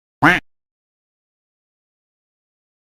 Play, download and share canard coincoin original sound button!!!!
canard-coincoin.mp3